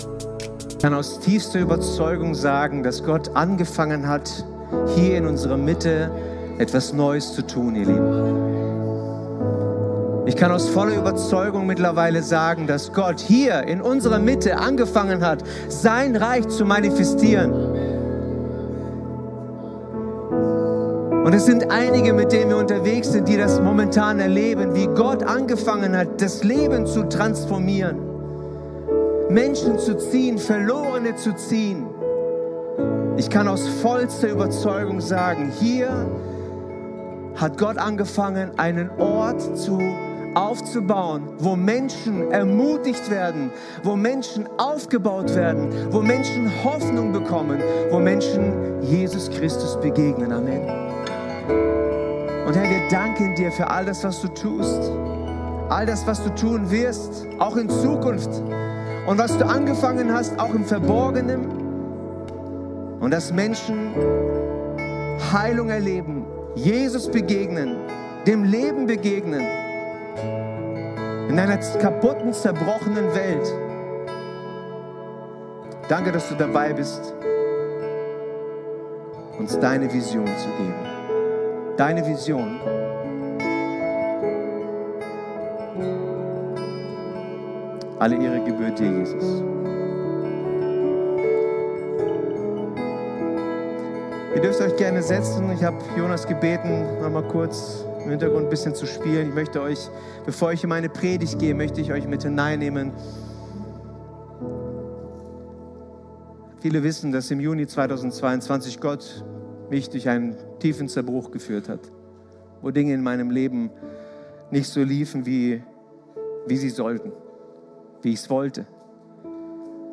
Sonntagspredigten
Wöchentliche Predigten des Christlichen Gemeindezentrums Albershausen